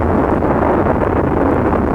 KART_turboLoop.ogg